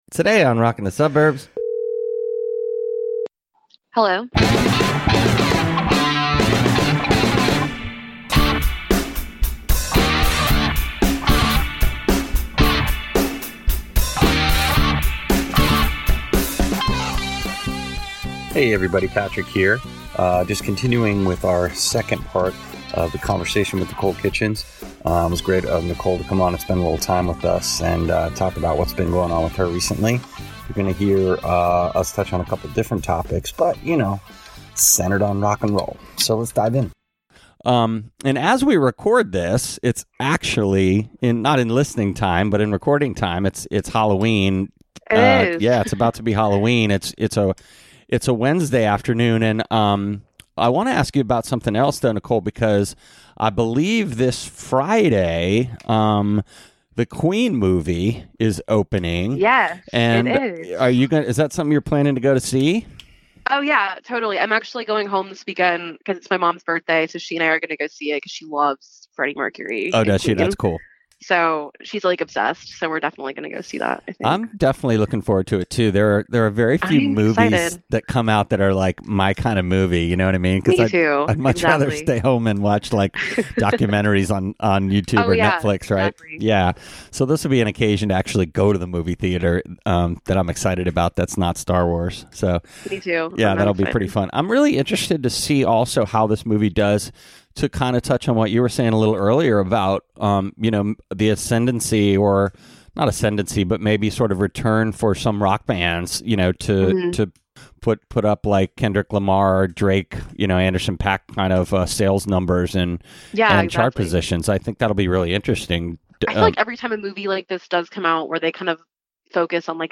More Talk With a College Student About Rock